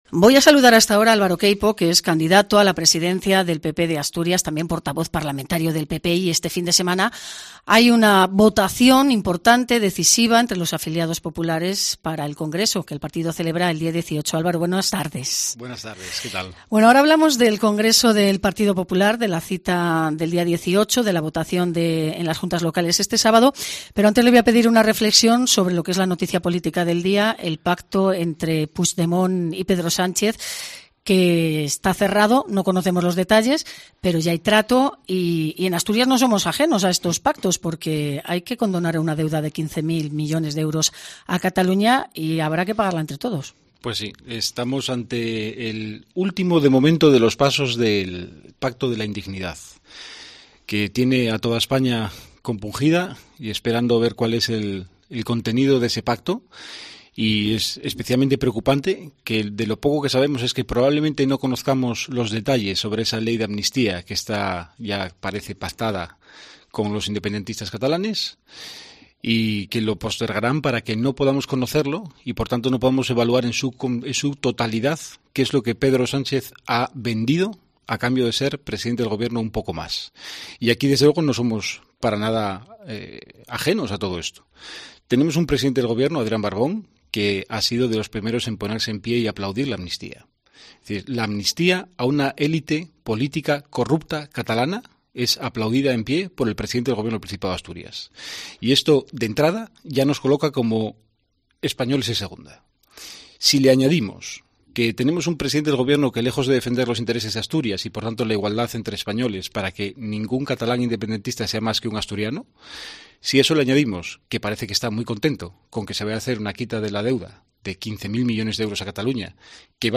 Entrevista en COPE a Álvaro Queipo